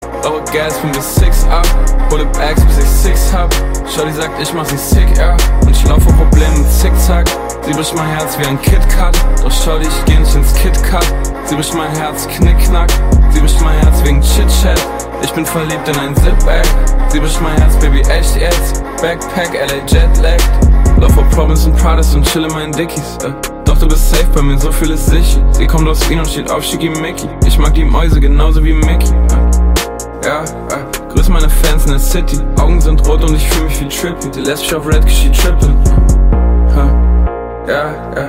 Kategorien: Rap/Hip Hop